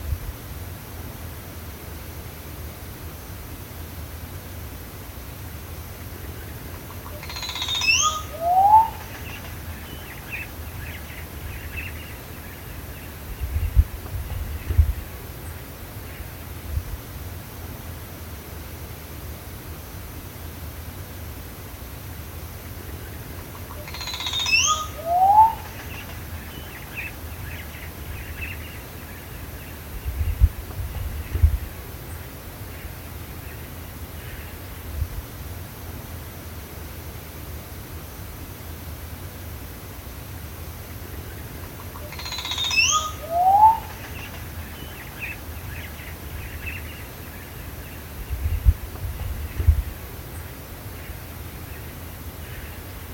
Yapú (Psarocolius decumanus)
Nombre en inglés: Crested Oropendola
Localidad o área protegida: Reserva Natural Privada Ecoportal de Piedra
Condición: Silvestre
Certeza: Fotografiada, Vocalización Grabada